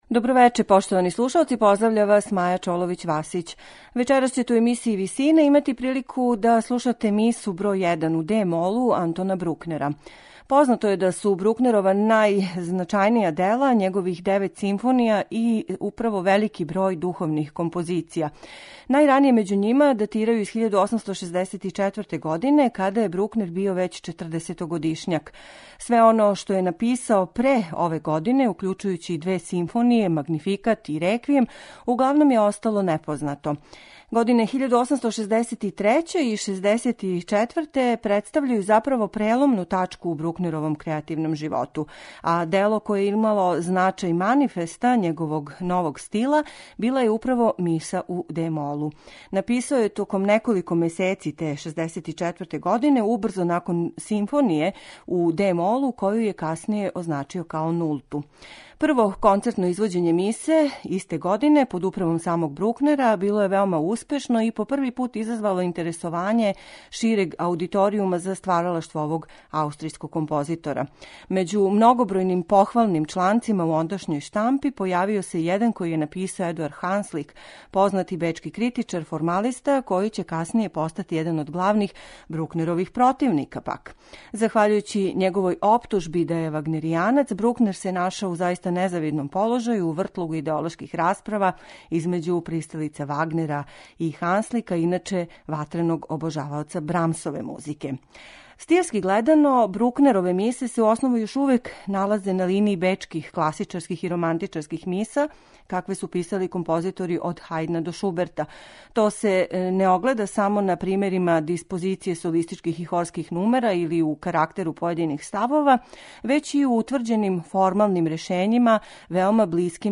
Оне сведоче о великом познавању свих средстава којима се служи полифони слог; мелодика се одликује оригиналношћу и чистотом певане линије, а хорске деонице пуним и сочним звуком.
квартета солиста, хора и Симфонијског оркестра Баварског радија